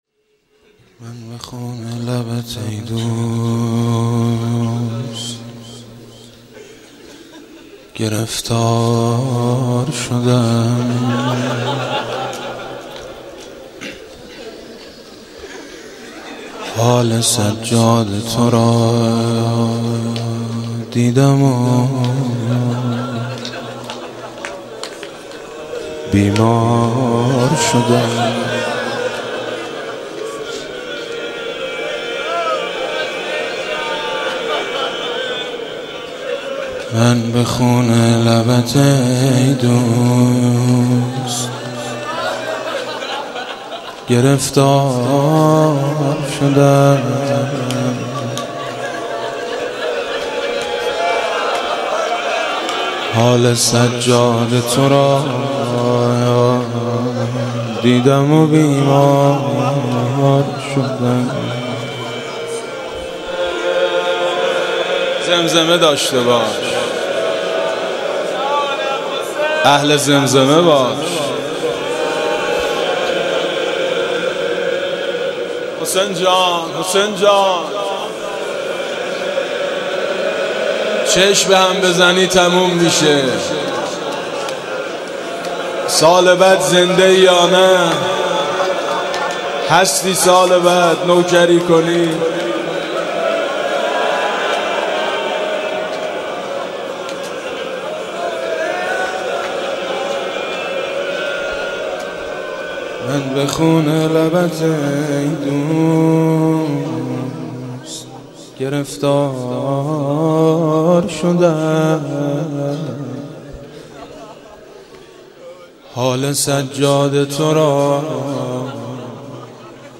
با صدای میثم مطیعی